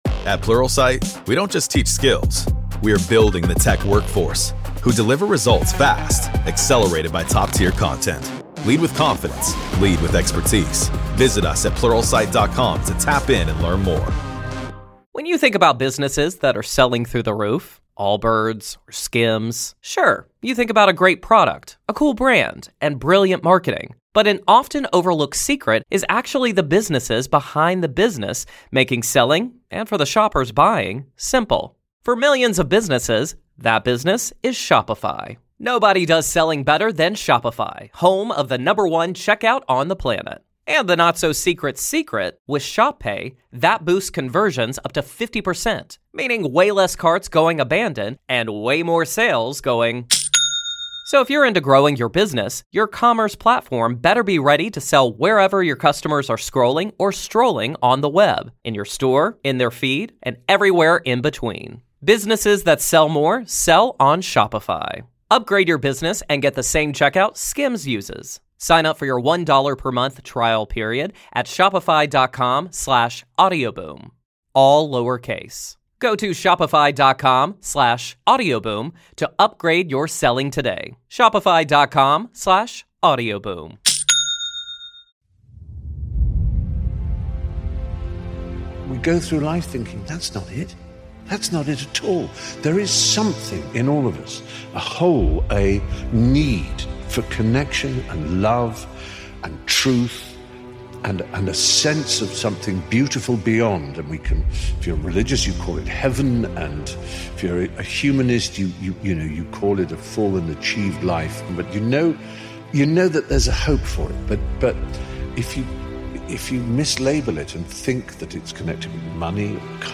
Speakers: Stephen Fry